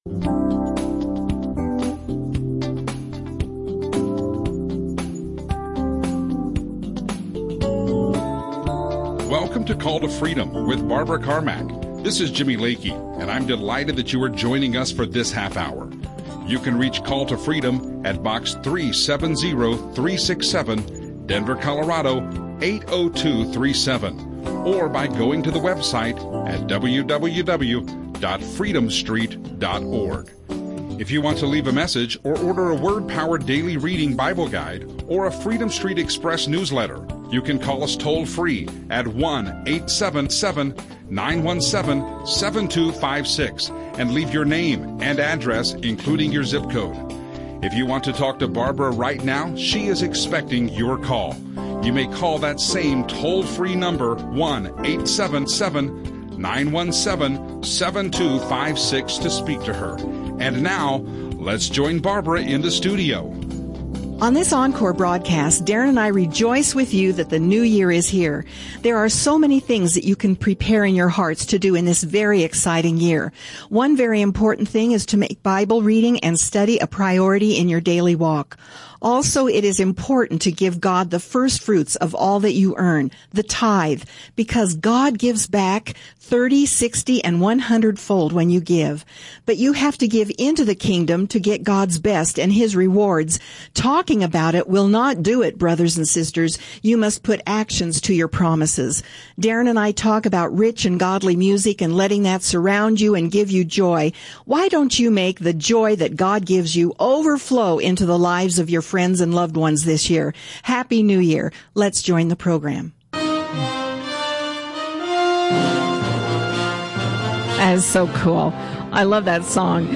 We talk about the songs that lift up GOD and JESUS and sing praises to His Name. They talk to a caller who exemplifies the singing to honor GOD.
Christian radio